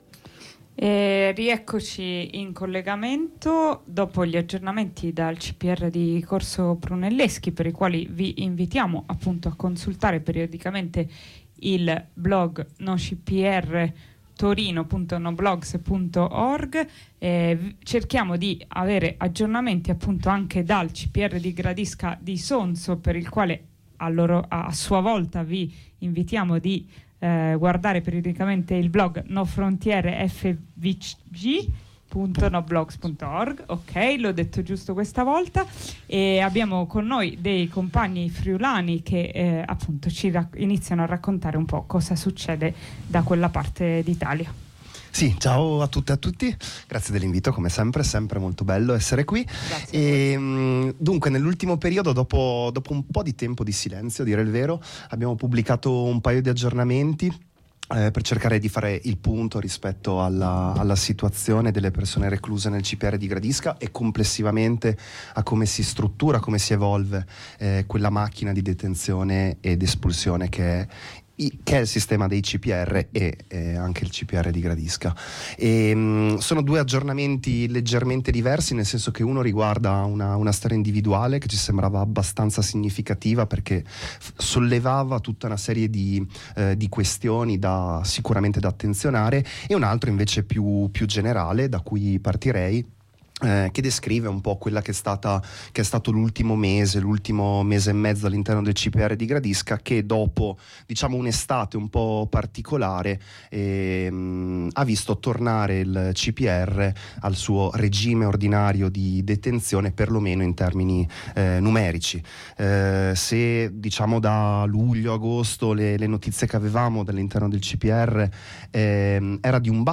In questa puntata di Harraga, in onda su Radio Blackout ogni venerdì dalle 15 alle 16, con alcuni compagni dell’assemblea contro il CPR del Friuli Venezia Giulia, abbiamo parlato degli ultimi aggiornamenti dal CPR di Gradisca d’Isonzo: informazioni preziose per mappare la macchina del razzismo di stato attraverso i trasferimenti, gli arresti sulla frontiera orientale, la cooperazione di Frontex nelle deportazioni.